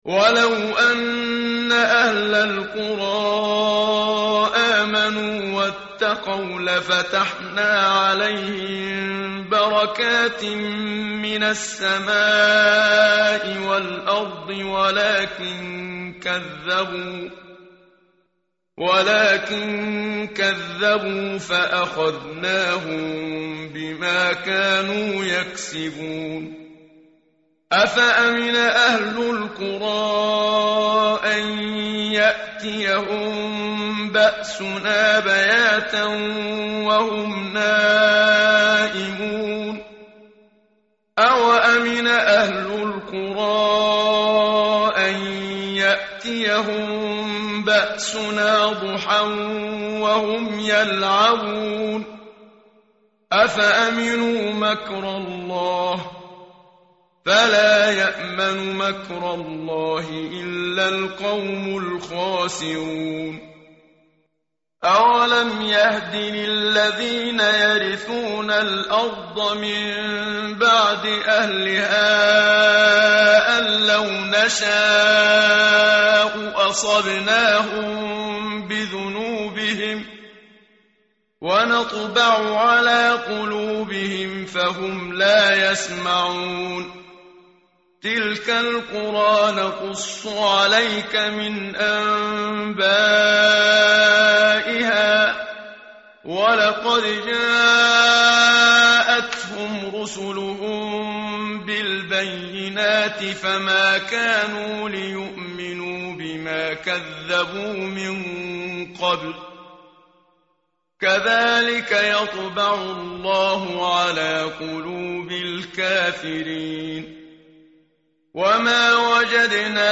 tartil_menshavi_page_163.mp3